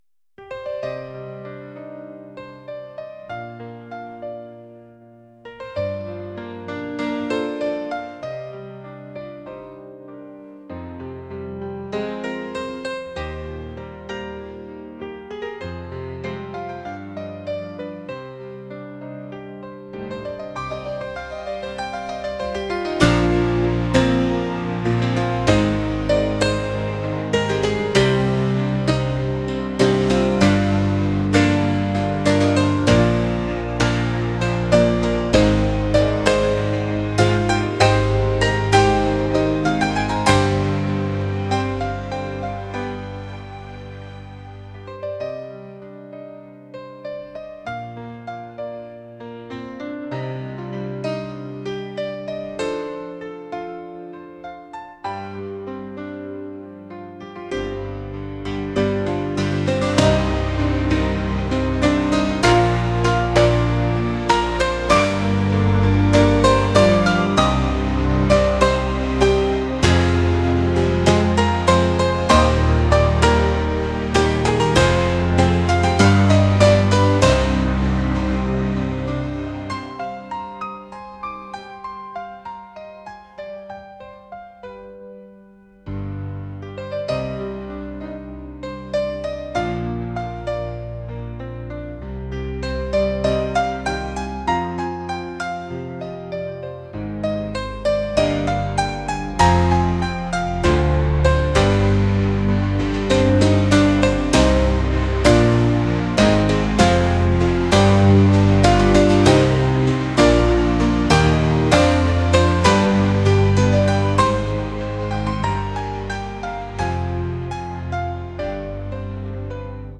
acoustic | pop | classical